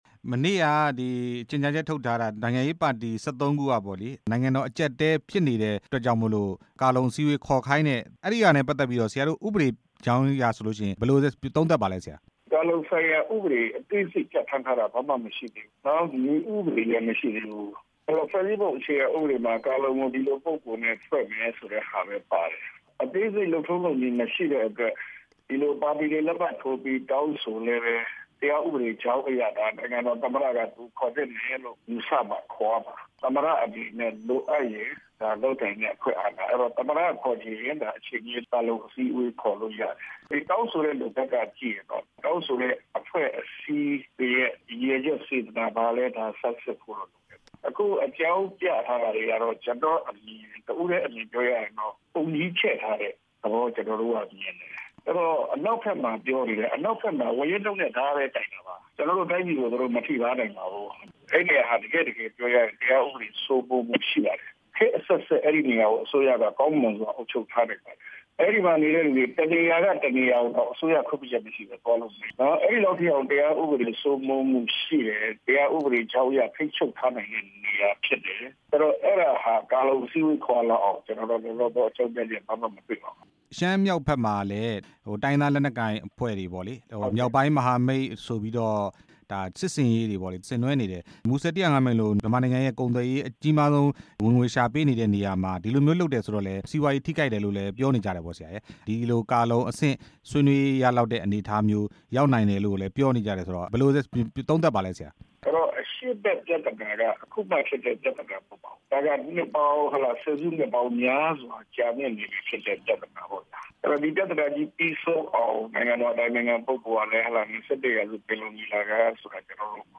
ကာလုံအစည်းအဝေး ခေါ်ဖို့ လိုအပ်နေပြီလားဆိုတာကို ရှေ့နေ ဦးကိုနီ နဲ့ မေးမြန်းချက်